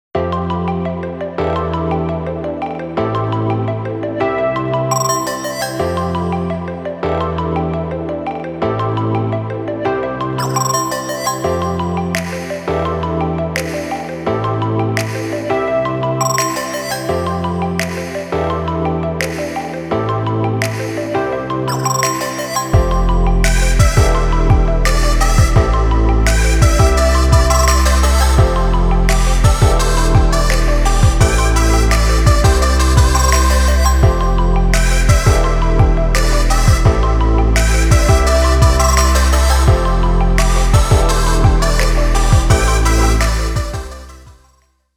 種別/サイズ 音楽CD - テクノ/ ＣＤ－ＲＯＭ 13p